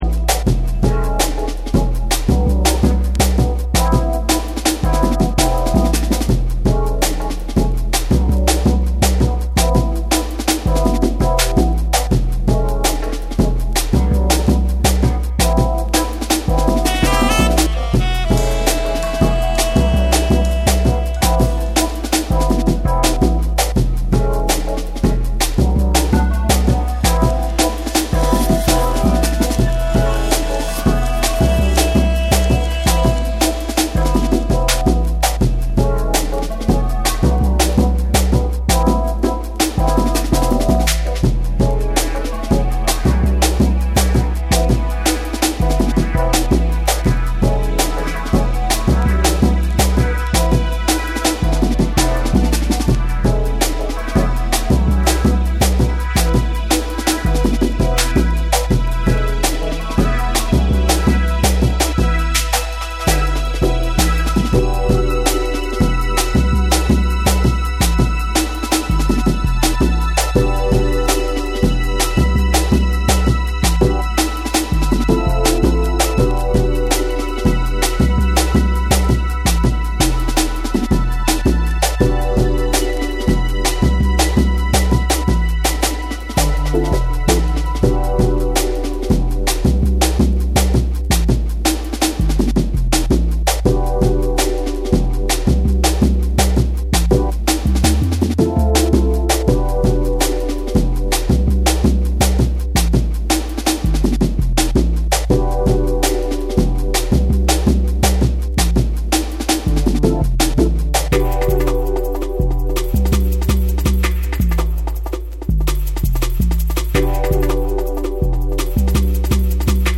JUNGLE & DRUM'N BASS